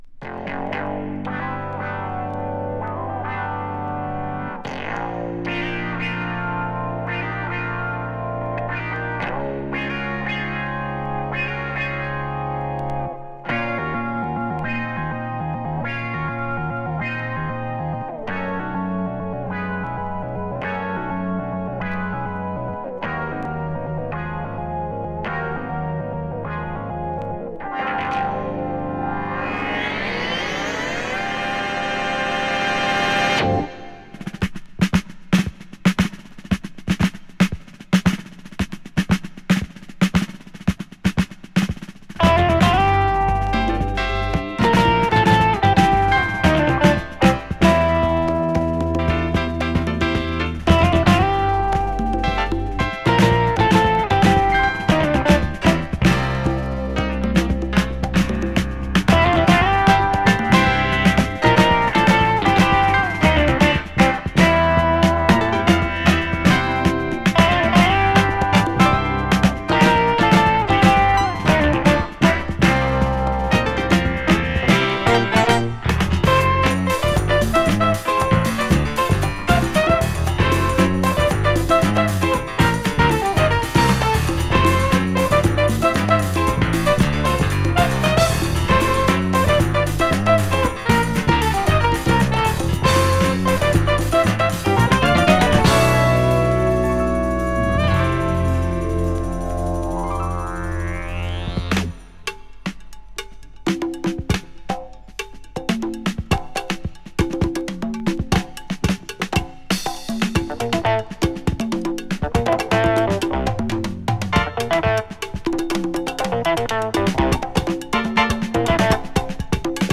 シンセ・ギターを使った不穏なイントロにブレイクも入る